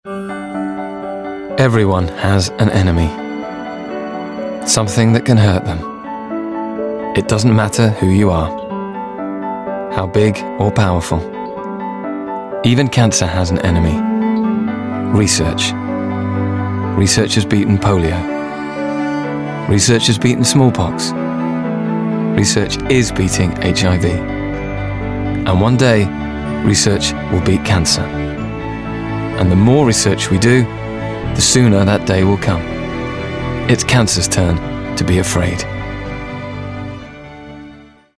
Neutral relaxed RP.
• Male
• London
• Standard English R P
Cancer Research Commercial